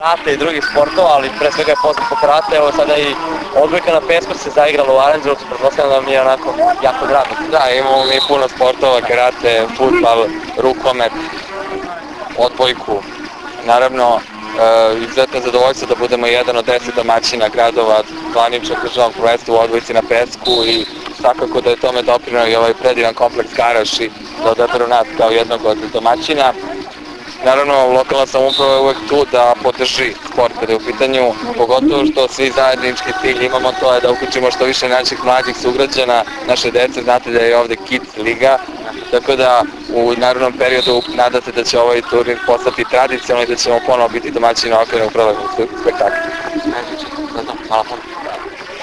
IZJAVA BOJANA RADOVIĆA, PREDSEDNIKA OPŠTINE ARANĐELOVAC